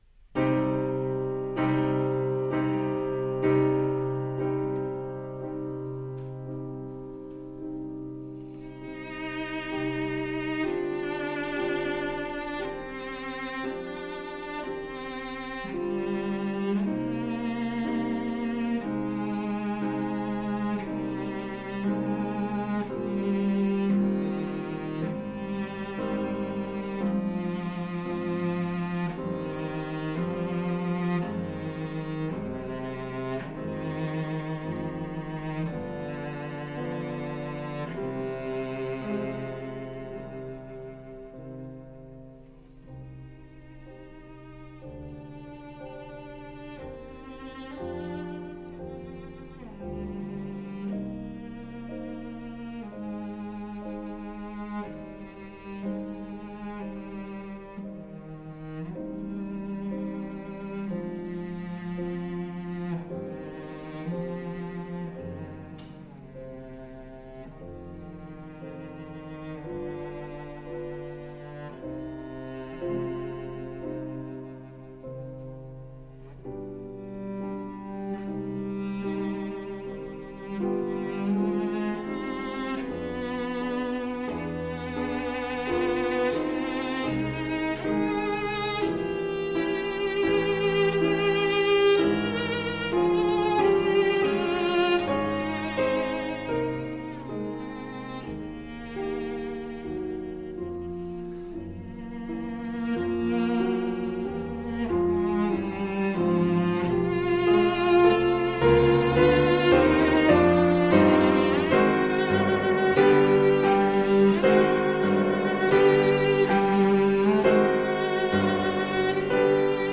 Cello
Classical (View more Classical Cello Music)
Audio: Boston - Isabella Stewart Gardner Museum